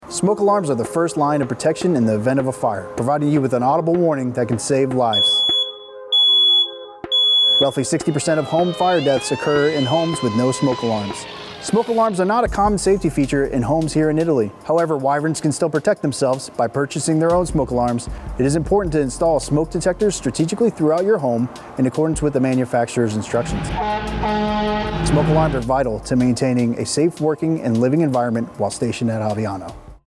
An American Forces Network (AFN) Aviano radio spot covering the importance of smoke alarms as part of the Fire Prevention Week campaign at Aviano Air Base, Italy.